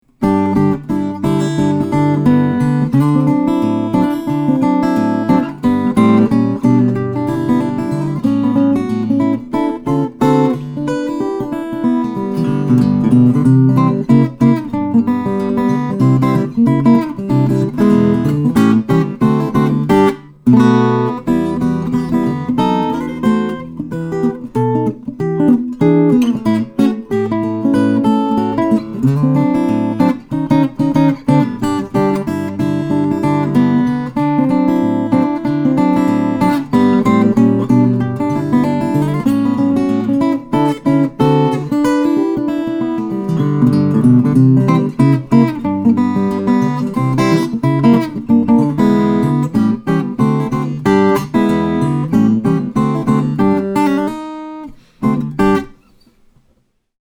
New Rainsong P12T Carbon/Graphite Composite/Carbon/Graphite Composite - Dream Guitars
The Carbon Graphite construction ensures laser perfection of action and tone every time you pull it out of the case, no need to worry about temperature or humidity problems here!